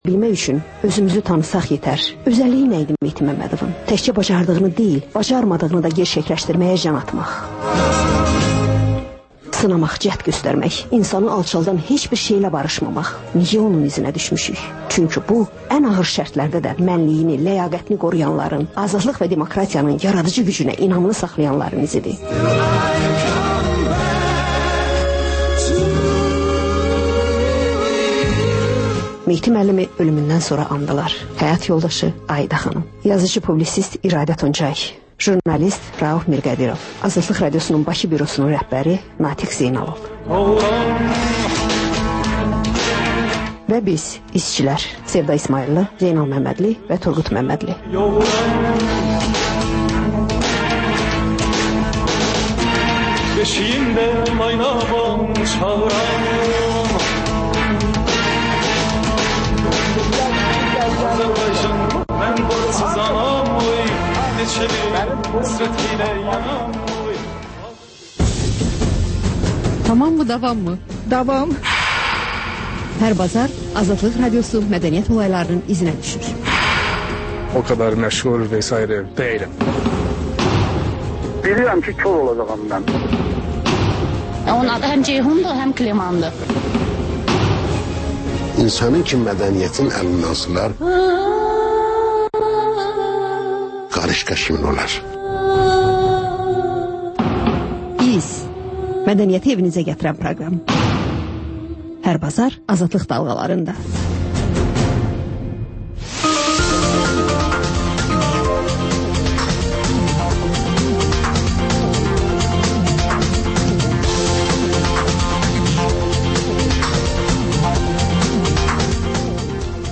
Xəbər-ətər: xəbərlər, müsahibələr, sonra PANORAMA verilişi: Həftənin aktual mövzusunun müzakirəsi